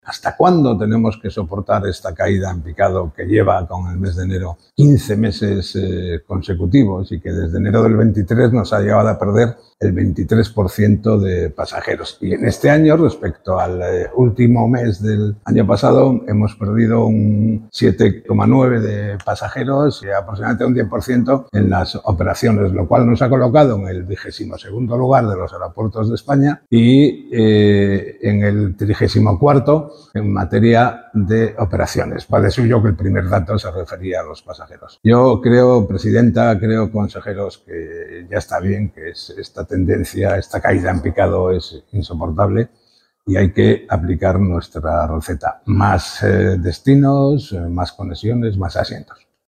Ver declaraciones de Javier López Marcano, diputado del Partido Regionalista de Cantabria y portavoz del PRC en materia de Turismo.